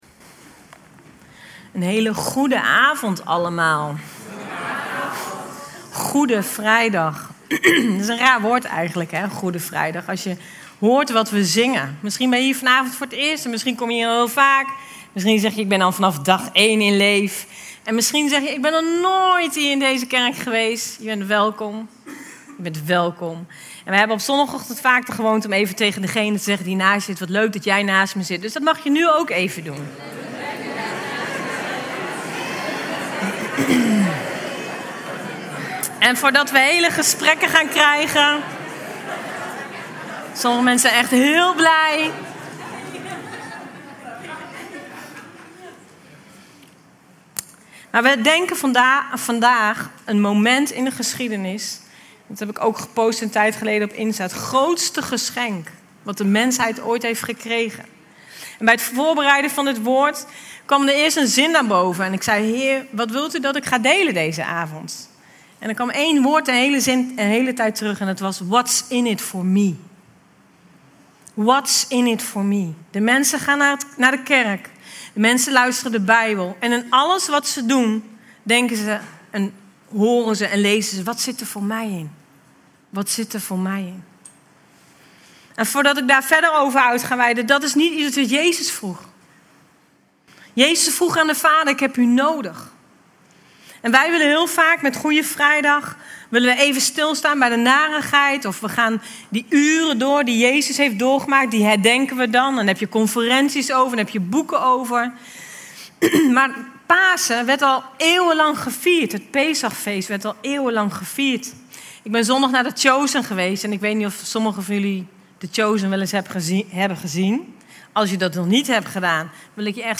Goede Vrijdag